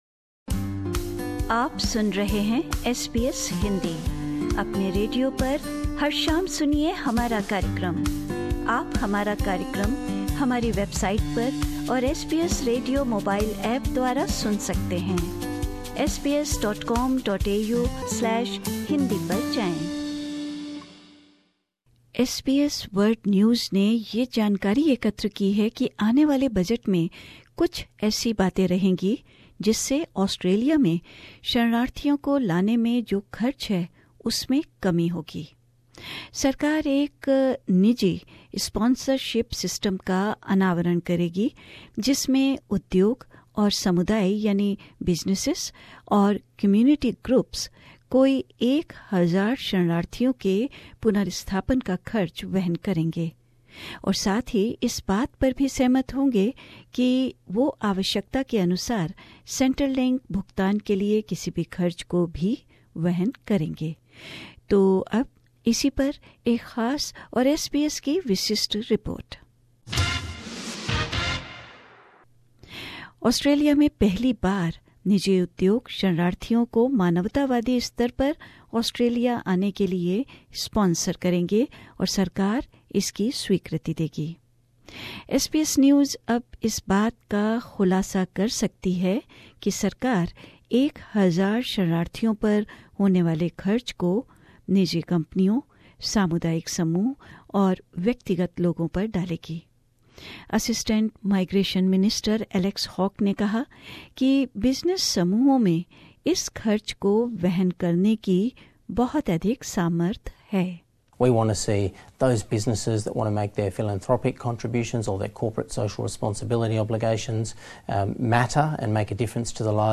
Alex Hawke, the assistant immigration minister, says there's plenty of appetite for it in the business community.